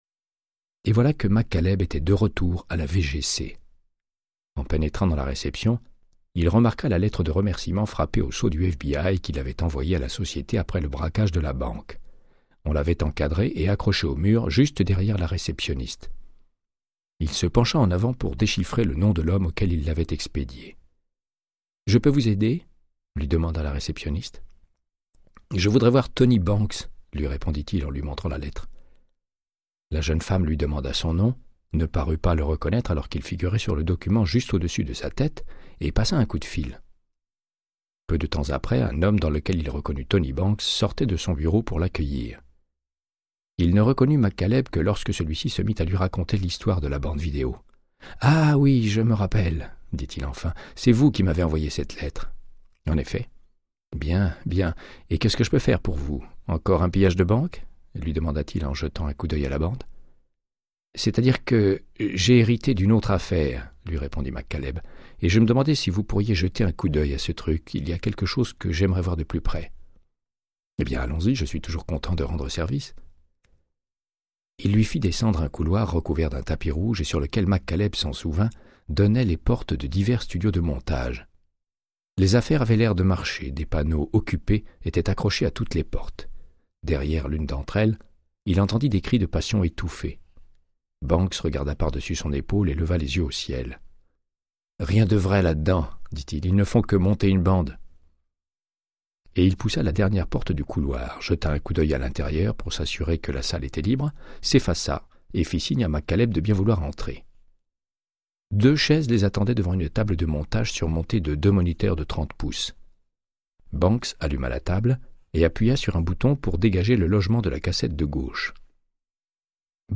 Audiobook = Créance de sang, de Michael Connellly - 57